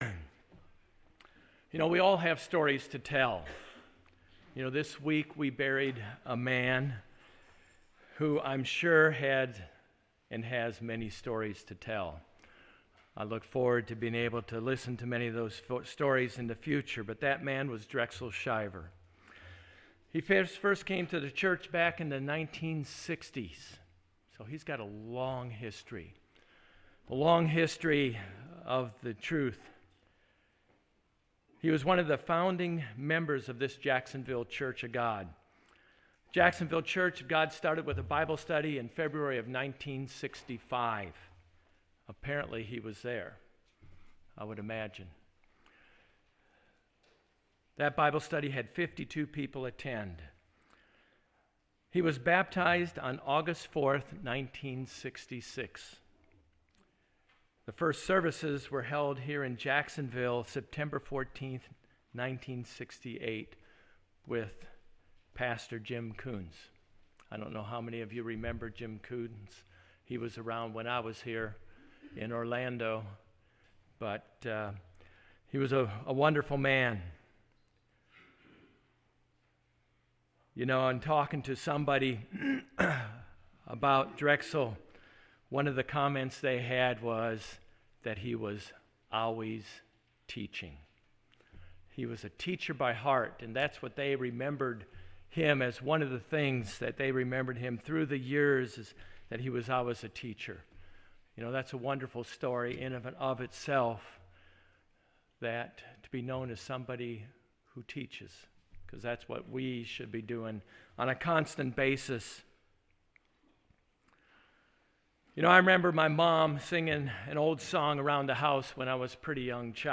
Sermons
Given in Jacksonville, FL